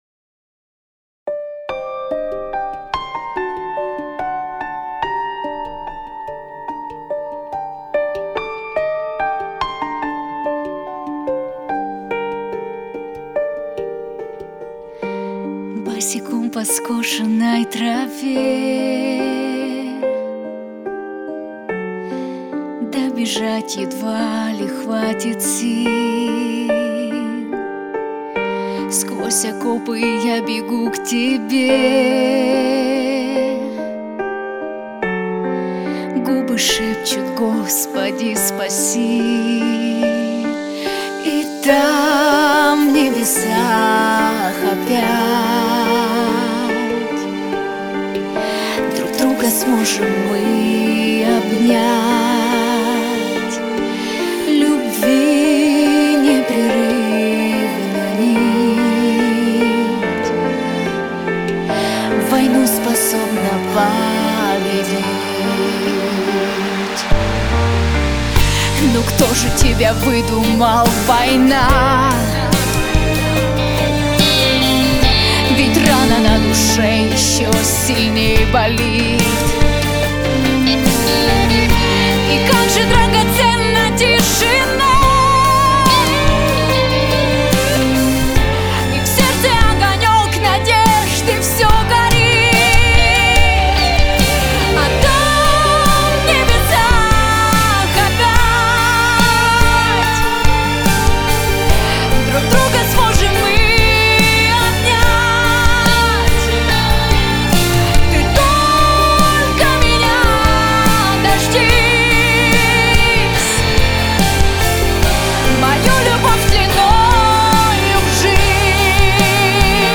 Категория: Военные песни